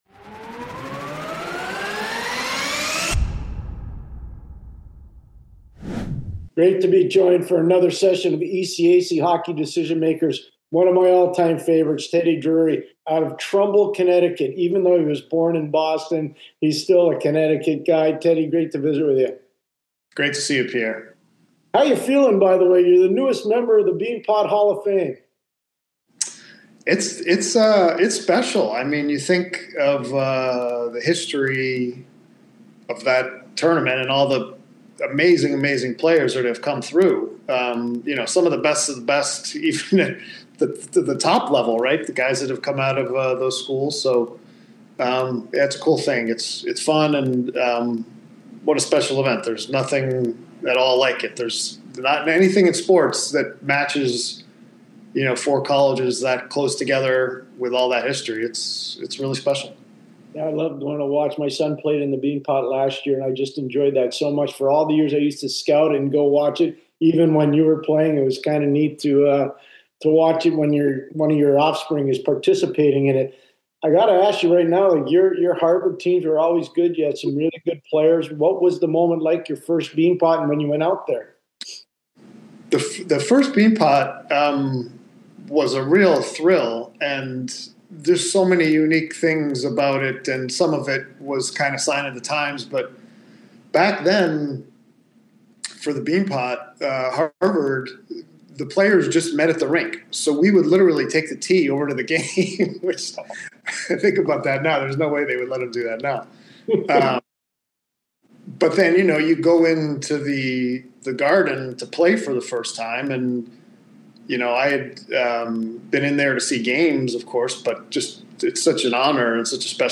February 09, 2026 In this episode of Decision Makers, Pierre McGuire sits down with Harvard legend and Beanpot Hall of Famer Teddy Drury.